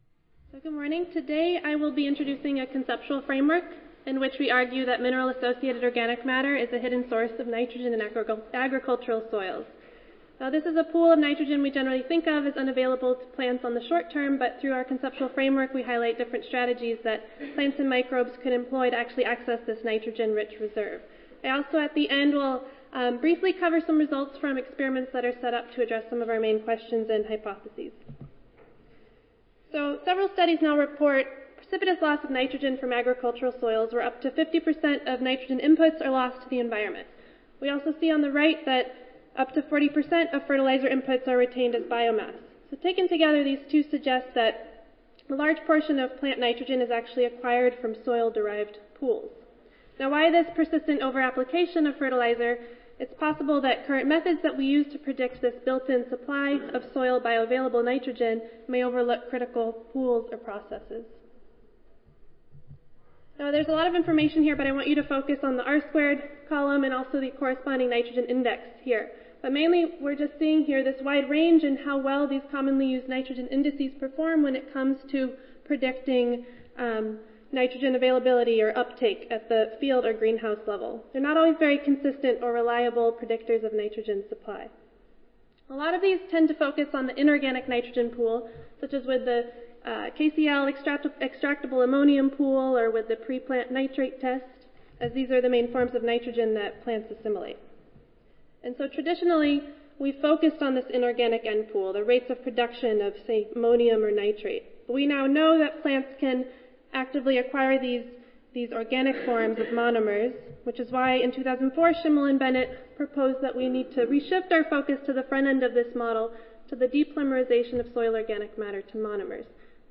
Session: Soil Biology and Biochemistry General Session I (ASA, CSSA and SSSA International Annual Meetings)
Recorded Presentation